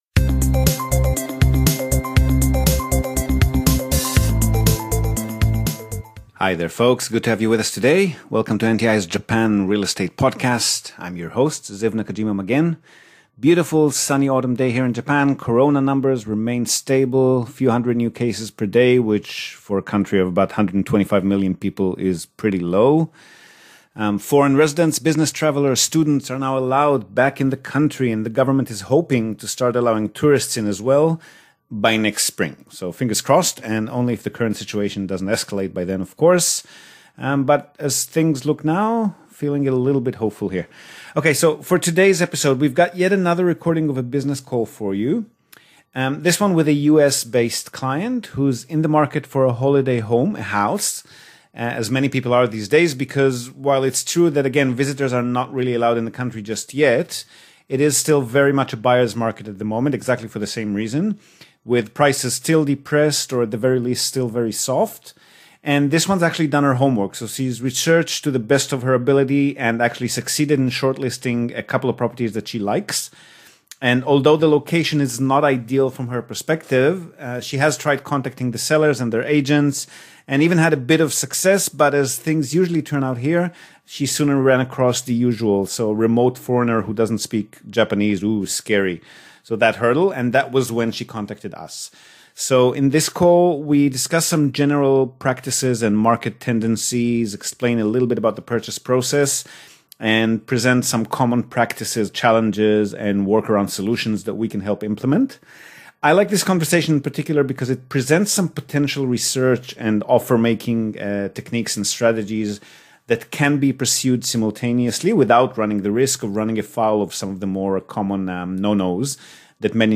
(most of) the Japan Real Estate Experts Panel is Back! This time we go super-casual and off-topic, chatting away about monkeys, games, quarantines stays, border closures, and short term stay man...